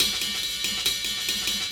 Ride 06.wav